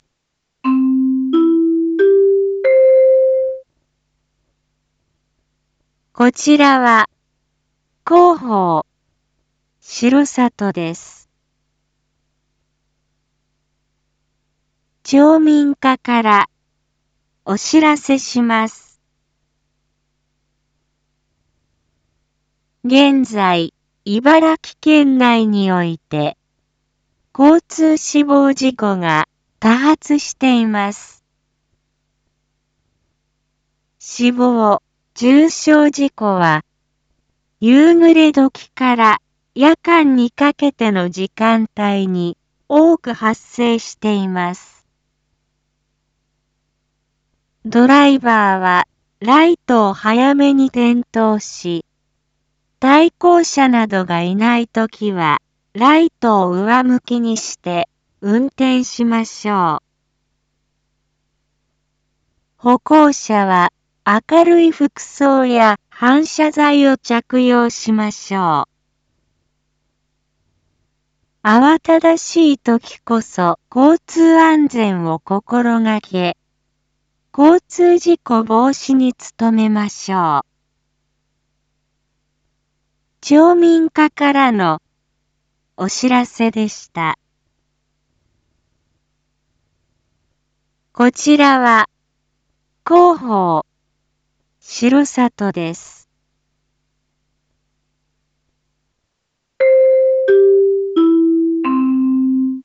一般放送情報
Back Home 一般放送情報 音声放送 再生 一般放送情報 登録日時：2023-01-20 19:01:44 タイトル：R5.1.20 19時放送分 インフォメーション：こちらは、広報しろさとです。